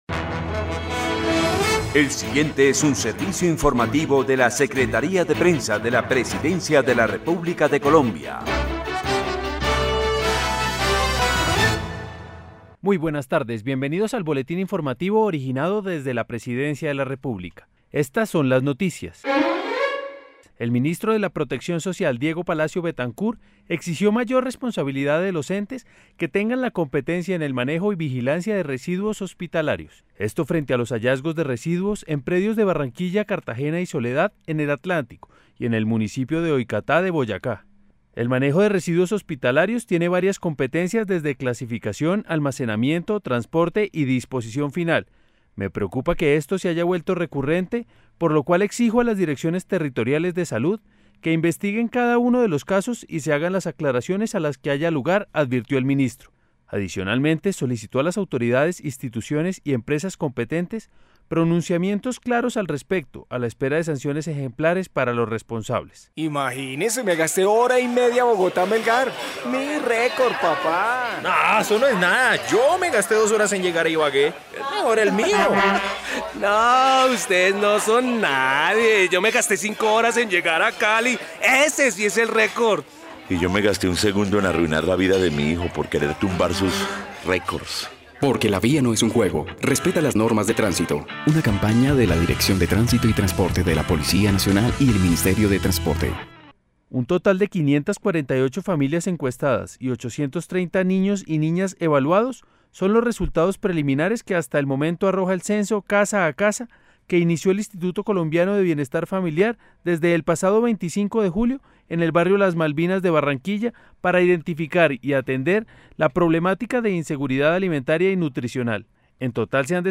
La Secretaría de Prensa de la Presidencia presenta a sus usuarios un nuevo servicio: El Boletín de Noticias, que se emite de lunes a viernes, cada tres horas, por la Radio Nacional de Colombia, en las frecuencias 570 AM y 95.9 FM.